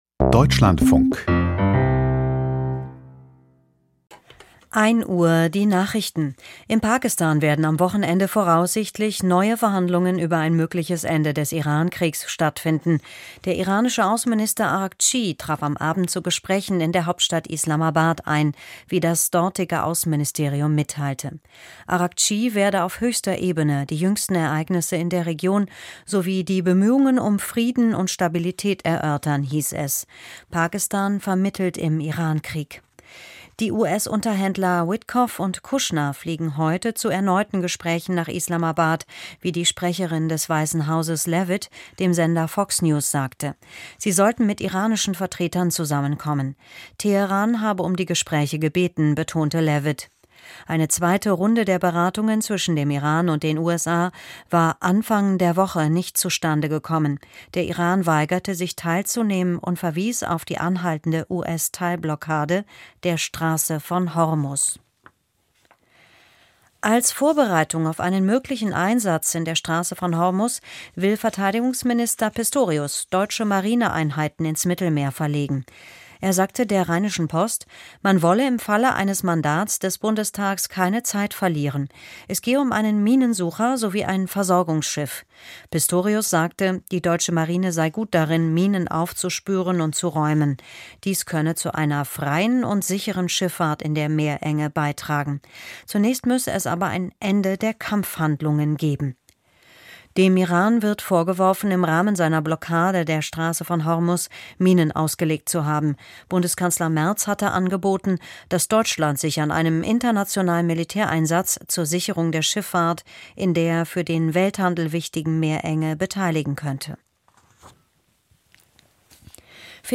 Die Nachrichten vom 25.04.2026, 01:00 Uhr